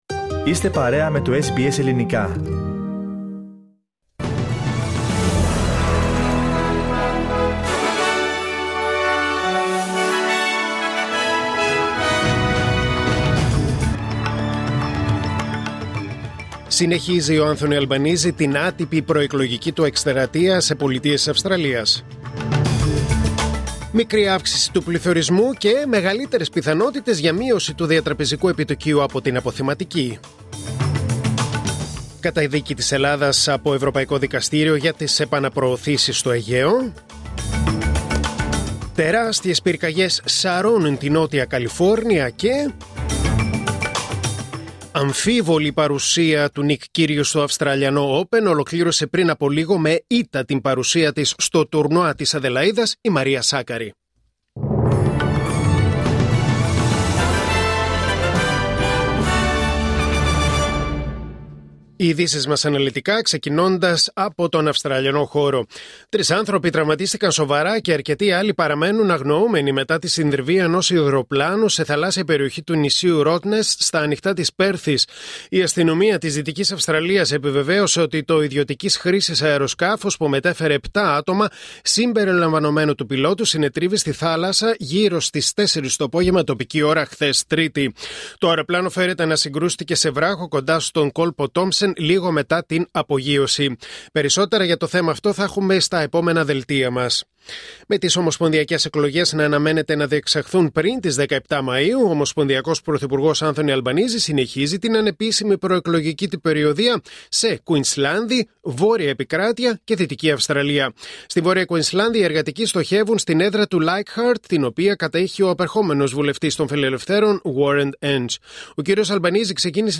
Δελτίο Ειδήσεων Τετάρτη 08 Ιανουαρίου 2025